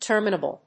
ter・mi・na・ble /tˈɚːm(ə)nəbltˈəː‐/
• / tˈɚːm(ə)nəbl(米国英語)
• / tˈəːm(ə)nəbl(英国英語)